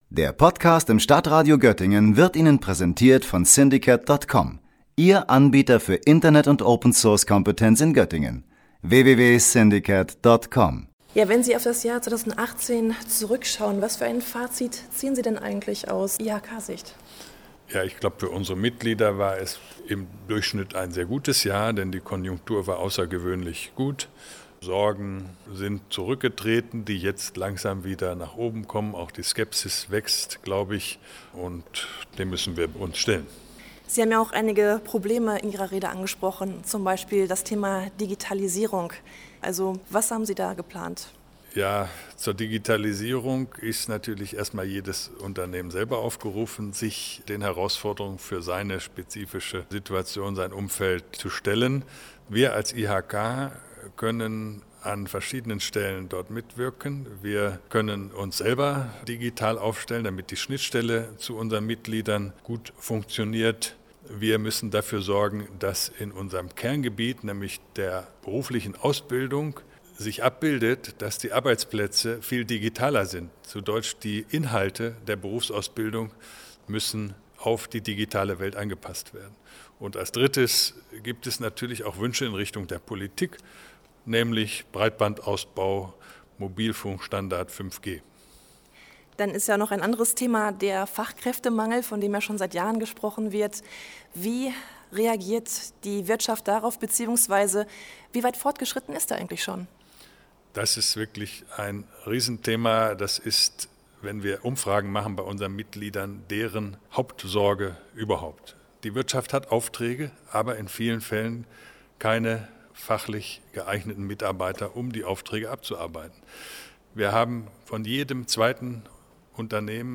Die Stimmung auf dem Neujahrsempfang der Industrie- und Handelskammer Hannover in der Göttinger Lokhalle war gut. Die südniedersächsischen Unternehmen haben im vergangenen Jahr von der guten Konjunktur profitiert und auch die Gewerbesteuereinnahmen sind deutlich gestiegen.